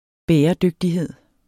Udtale [ ˈbεːʌˌdøgdiˌheðˀ ]